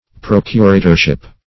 Search Result for " procuratorship" : The Collaborative International Dictionary of English v.0.48: Procuratorship \Proc"u*ra`tor*ship\, n. The office or term of a procurator.
procuratorship.mp3